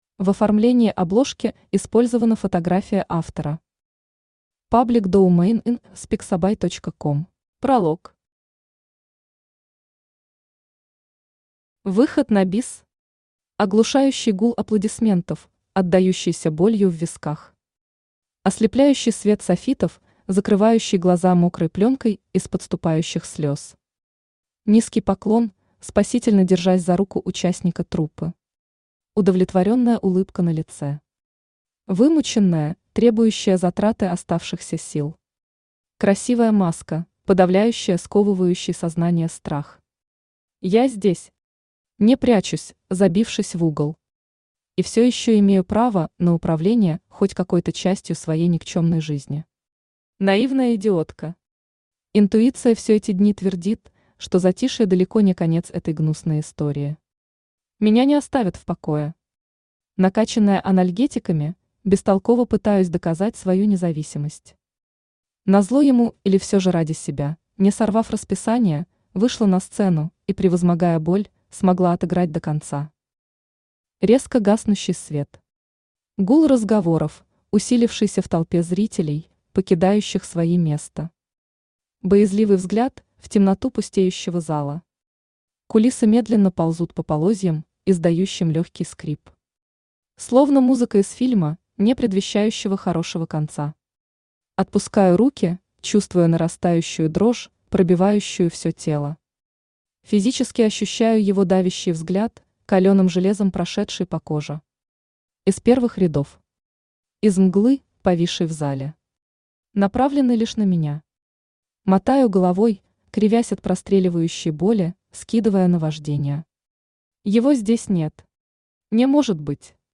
Аудиокнига Replay | Библиотека аудиокниг
Aудиокнига Replay Автор Юлия Прим Читает аудиокнигу Авточтец ЛитРес.